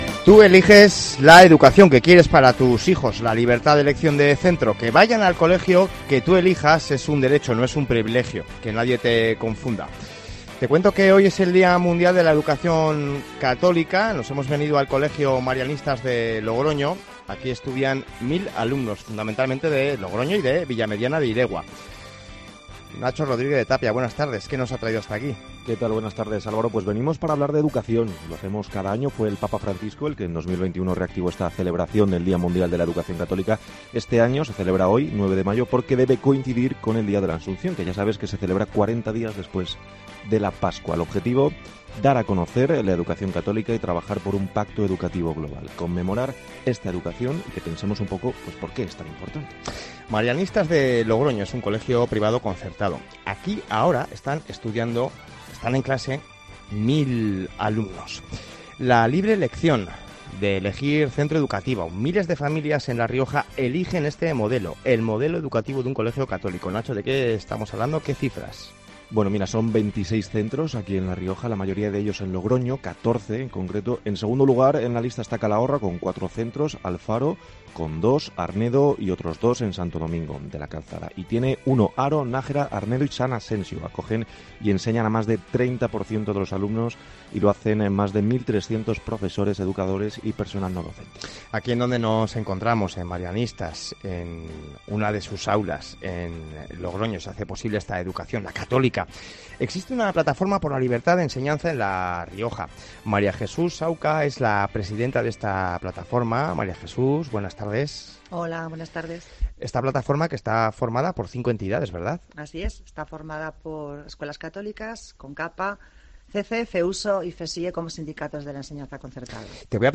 COPE Rioja desde el colegio Santa Marina Marianistas de Logroño